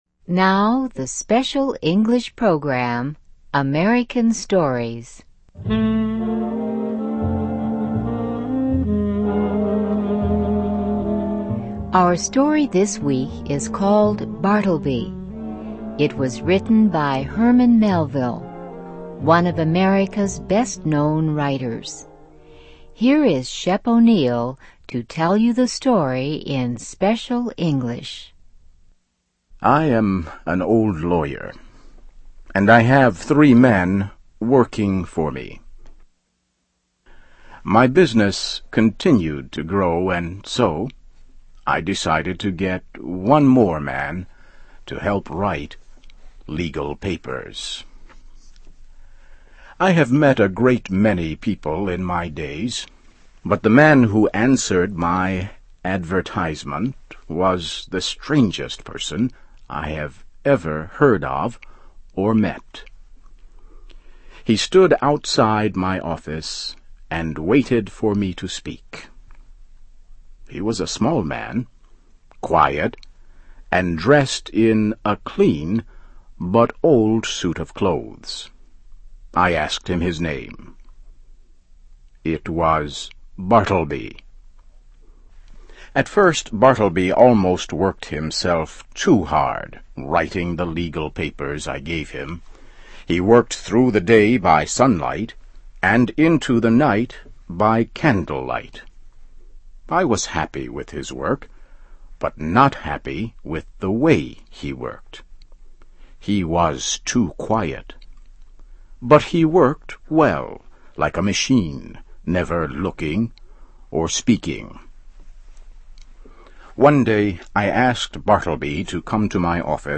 Now, the Special English program, AMERICAN STORIES.